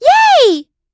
Estelle-Vox_Happy4.wav